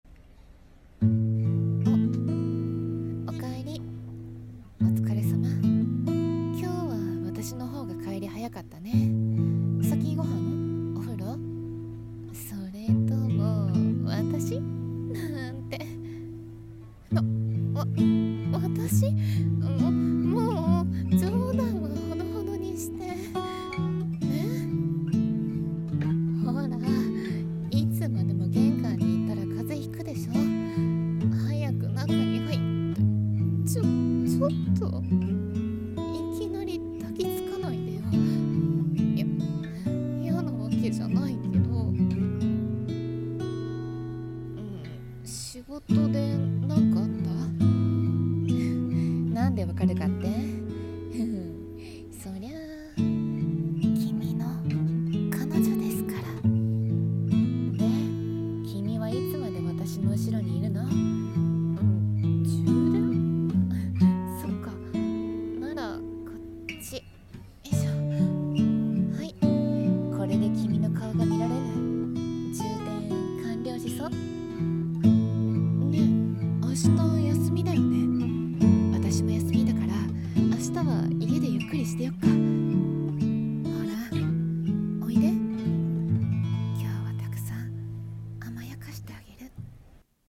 【声劇】おかえりの充電 BGM：BGM 癒し